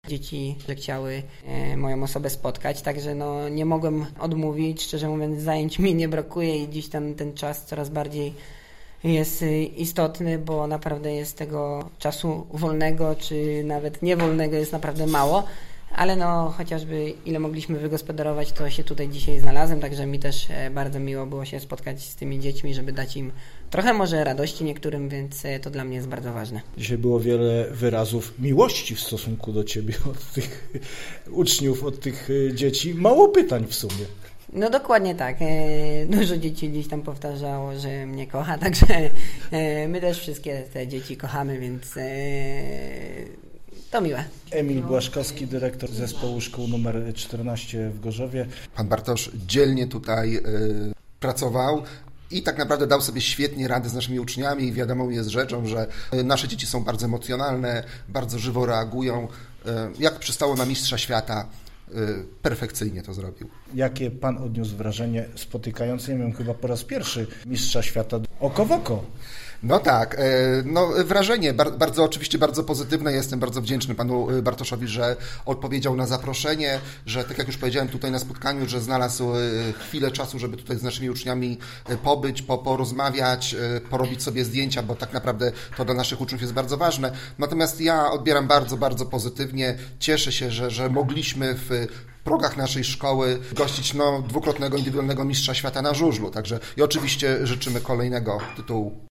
Mimo to dwukrotny Indywidualny Mistrz Świata zdołał wcisnąć do swojego terminarza wizytę w gorzowskim Zespole Szkół Specjalnych nr 14, gdzie w wypełnionej po brzegi niewielkiej sali gimnastycznej spotkał się z uczniami tej placówki.